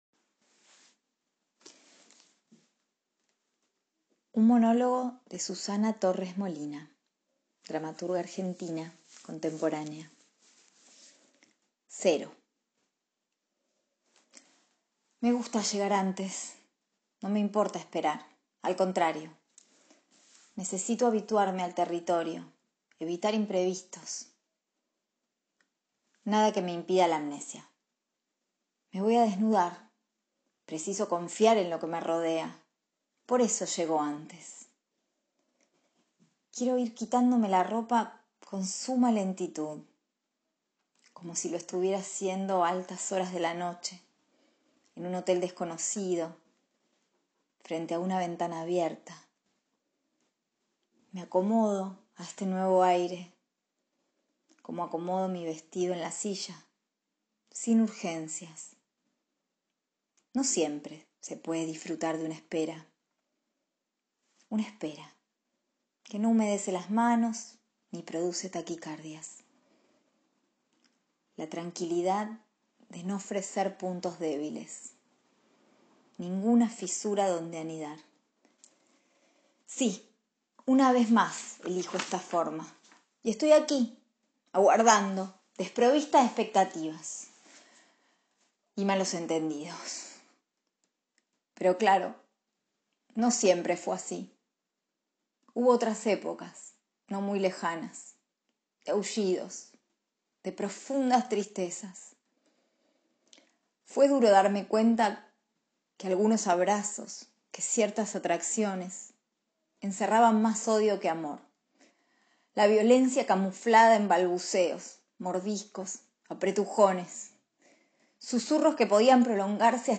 «Monólogo de obra de teatro Cero» de Susana Torres Molina